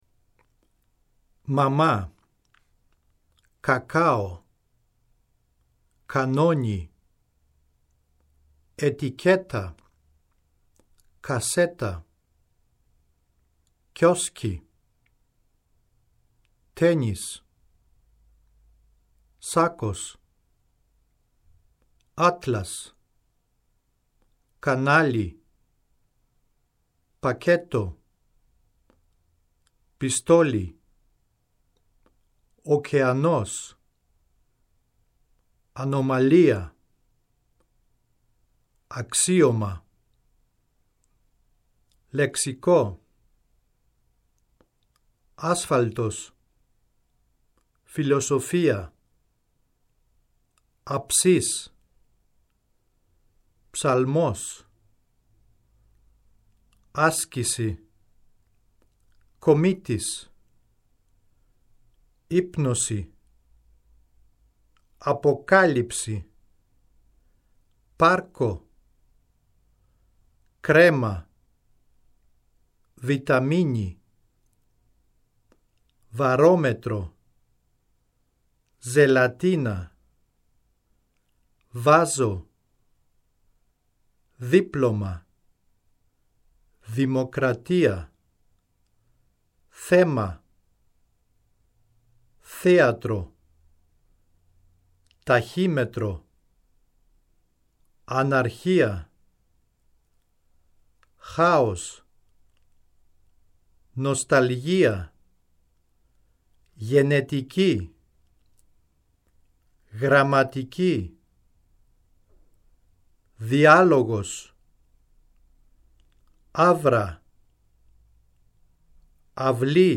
– Ein Grieche spricht die Wörter auf dem Blatt.